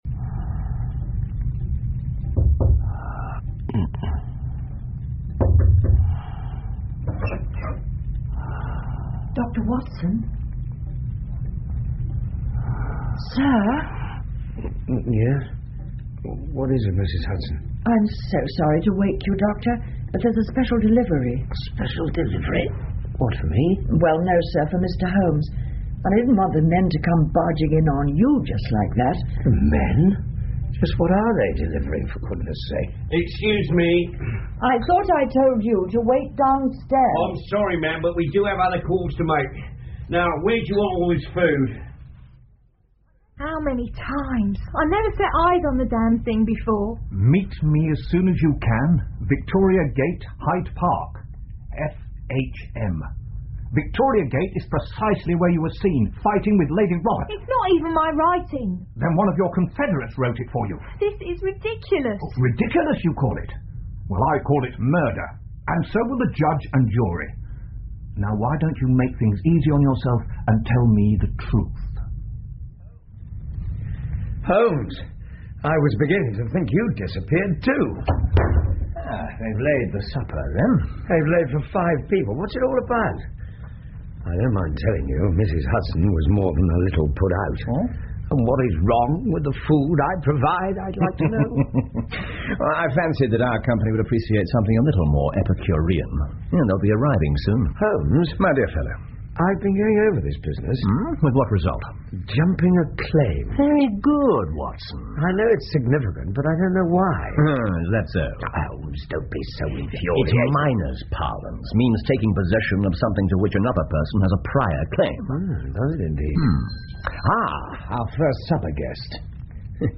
福尔摩斯广播剧 The Noble Bachelor 7 听力文件下载—在线英语听力室